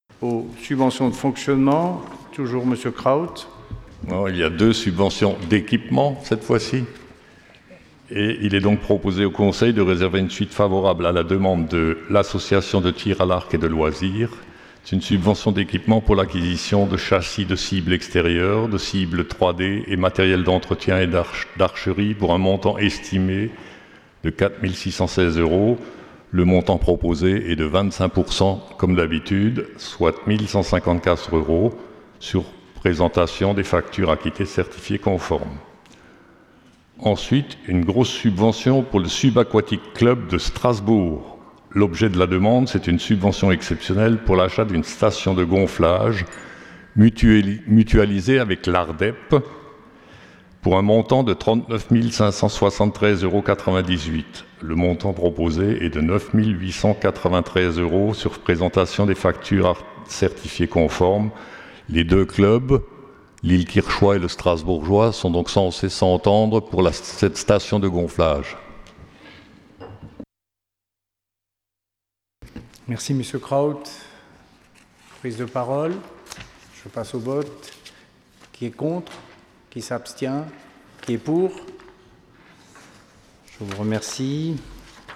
Point 4 : Subventions d’équipement – exercice 2020 Conseil Municipal du 13 janvier 2020